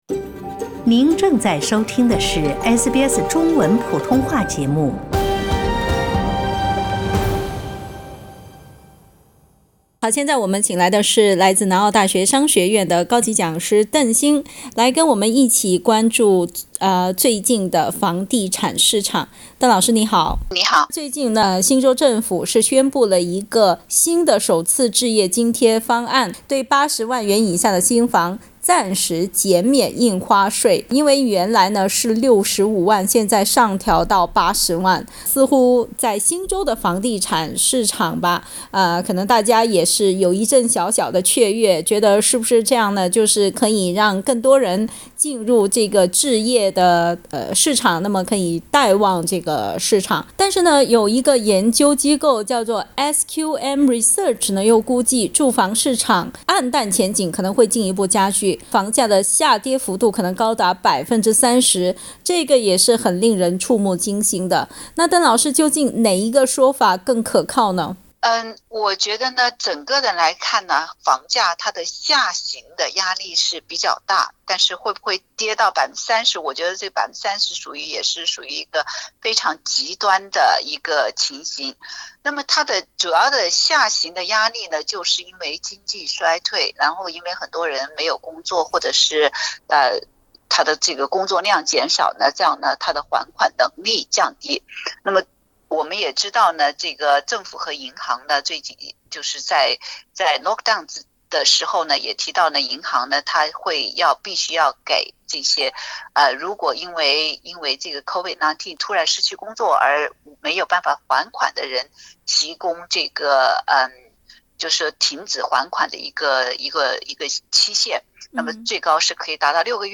但是，据研究机构SQM Research估计，住房市场黯淡前景进一步加剧，房价下跌幅度可能高达30％。 到底是政府托市成功的说法有说服力，还是房价下跌30%的预测更贴近实际? 点击图片收听详细报道。